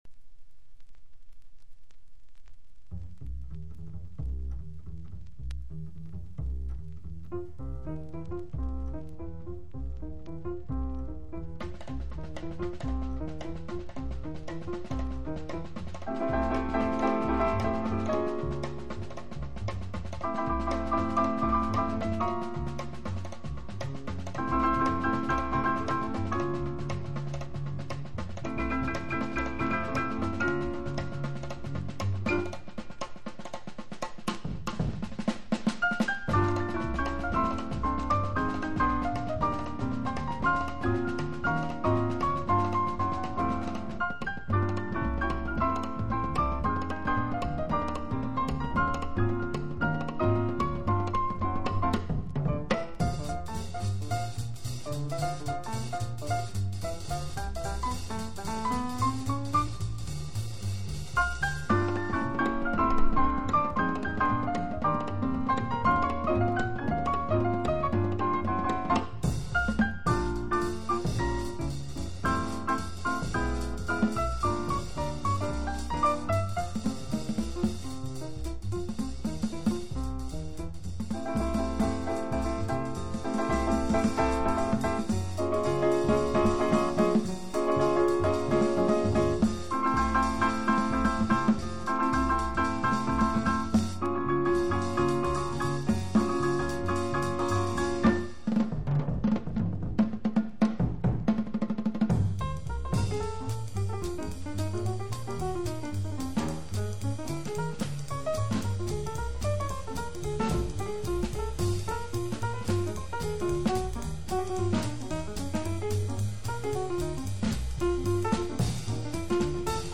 Genre PIANO TRIO